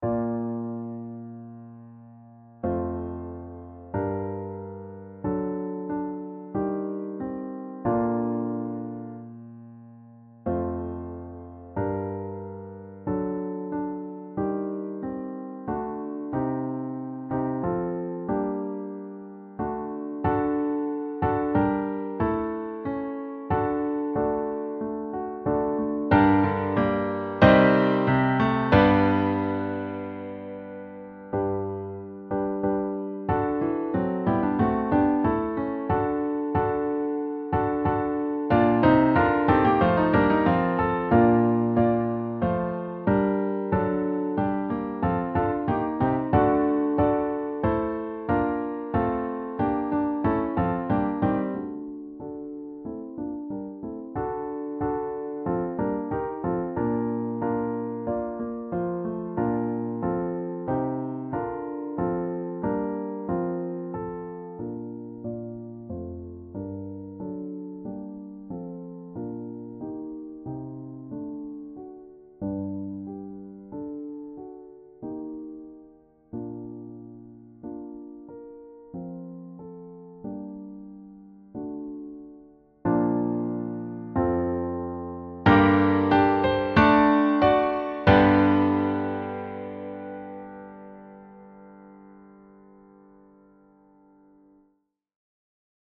à 2 chœurs